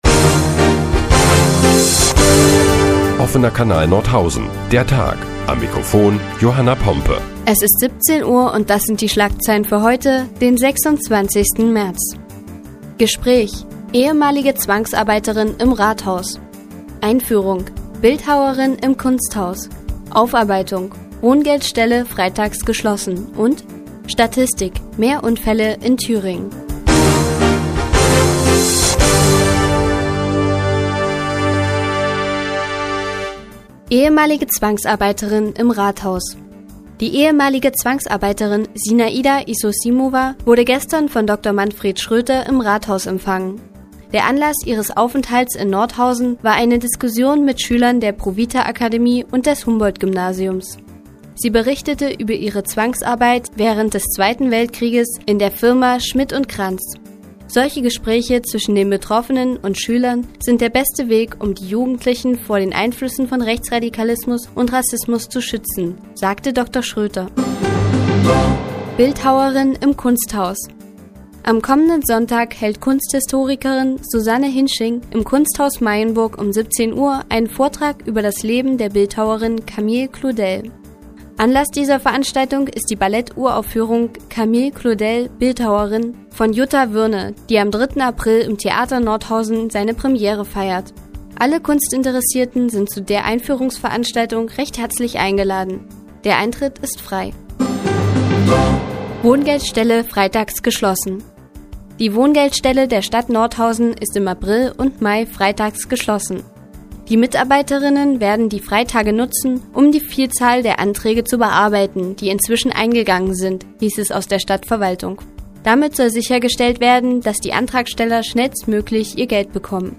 Die tägliche Nachrichtensendung des OKN ist nun auch in der nnz zu hören. Heute geht es unter anderem um die Unfallstatistik in Thüringen und um eine ehemalige Zwangsarbeiterin im Rathaus.